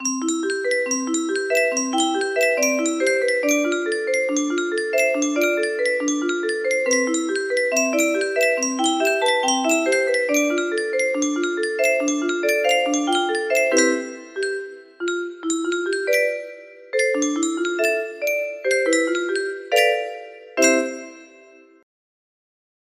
Gentle River music box melody
Short original tune - my first composition for this instrument.